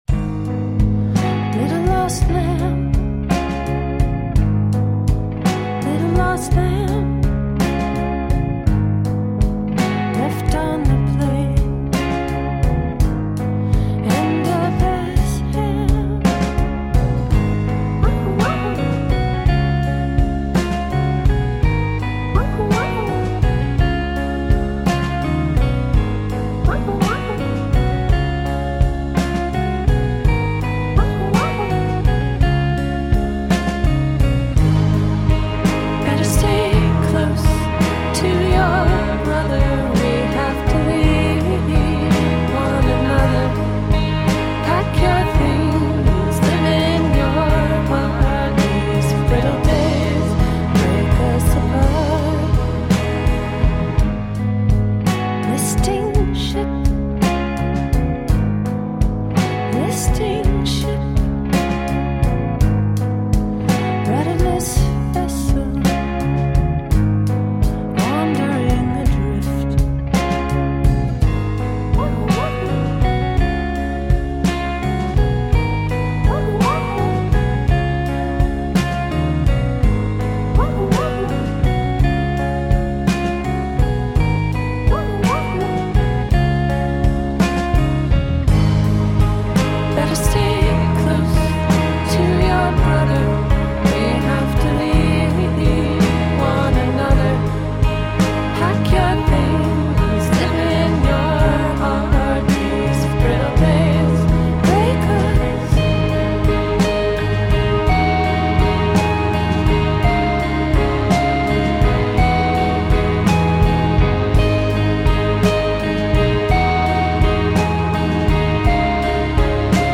Dreamy, slightly mournful indie pop.
Tagged as: Electro Rock, Pop